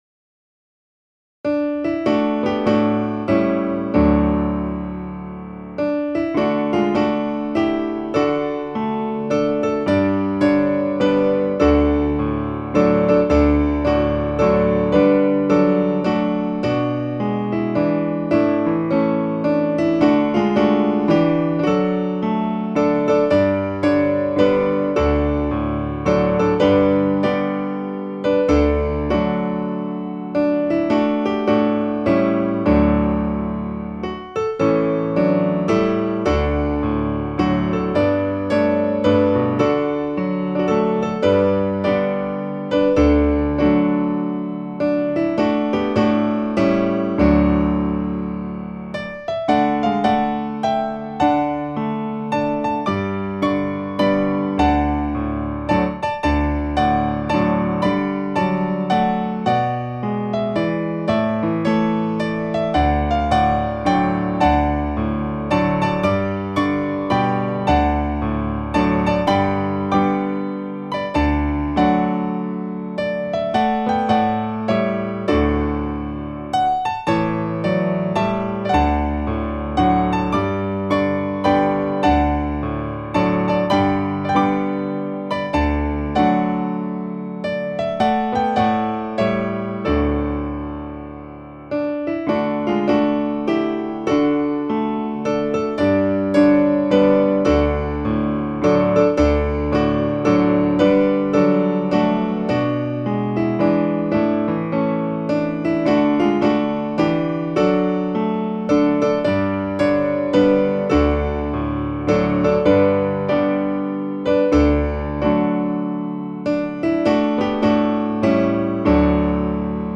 Posted in hymns | Comments Off on Tis The Blessed Hour Of Prayer